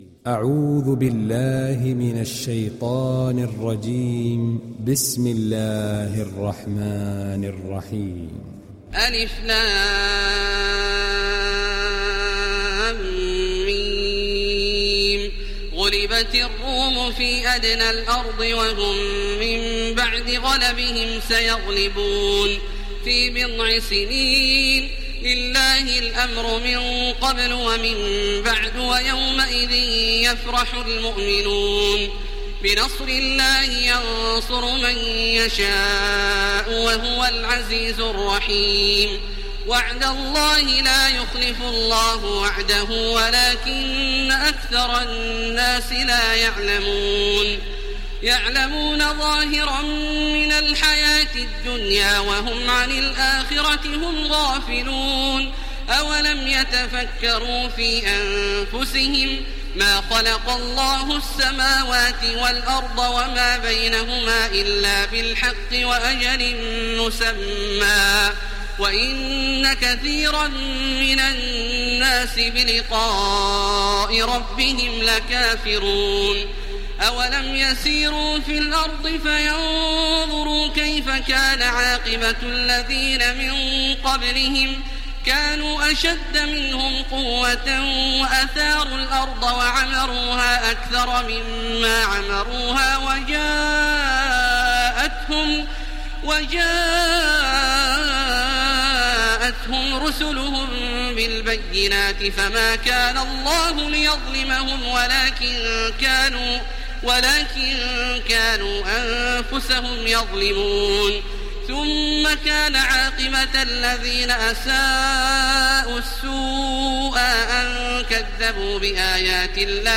Download Surah Ar Rum Taraweeh Makkah 1430